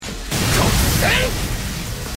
yujis black flash Meme Sound Effect
Category: Anime Soundboard